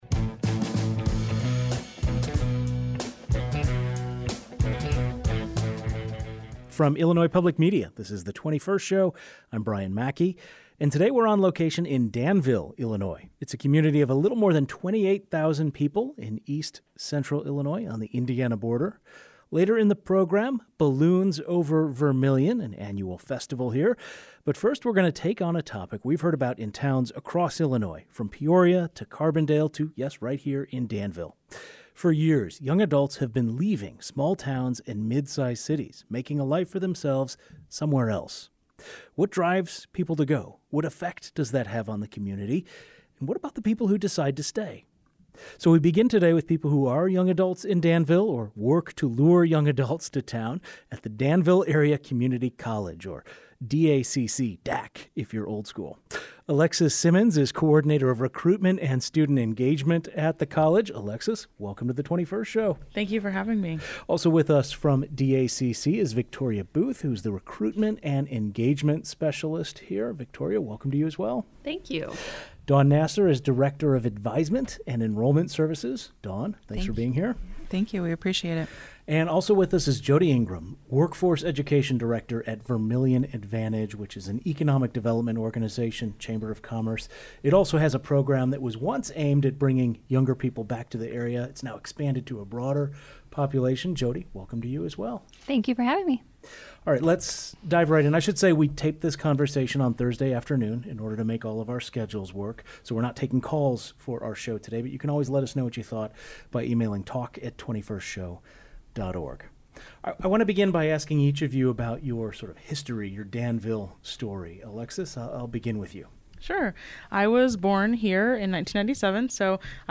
On the Friday, July 11, 2025 edition of The 21st Show, we took our program on the road to Vermilion County, which sits on the Illinois/Indiana border. We talked to a panel of students about why they are less likely to stay in the small communities where they grew up.
Our conversation took place at Danville Area Community College.